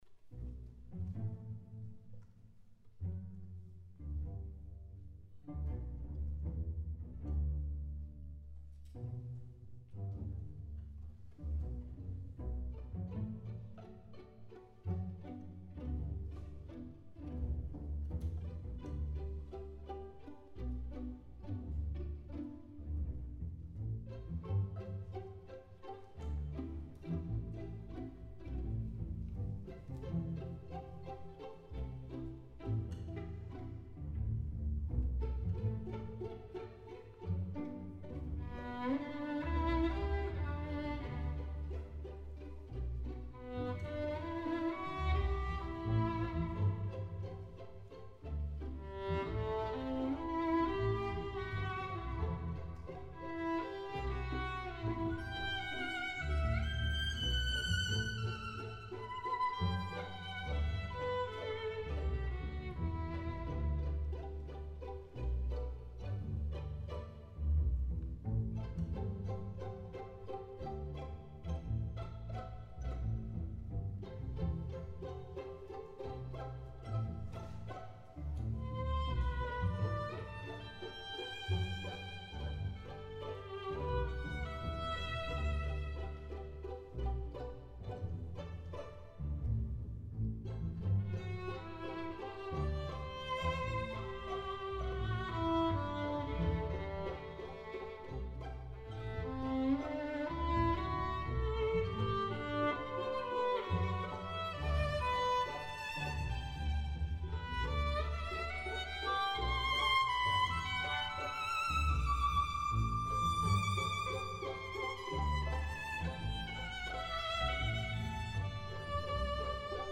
konzert für kammerorchester und solovioline / langsamer satz
(der maestro spielt höchstdero die violine)
sie klingt nicht sehr neu, ist aber spritzig und einfallsreich, wie ich finde. ich suche noch nach einer idee, wie der komponist seine werke wirklich vermarkten kann. dafür hat er leider kein talent (und ich nur wenig)
Vor allem in den höheren Lagen, da singt und fliegt er leidenschaftlich, und das mit einer super Intonation. Toller Sound auch.
Bei der "Rhythmusgruppe" wird, finde ich, hörbar, dass nicht viel Zeit zum Proben war :-)
aber ich empfinde es wie du: in den höheren lagen ist eine wunderschöne "liebliche" (im besten sinne) innigkeit zu spüren.